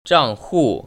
[zhànghù] 장후  ▶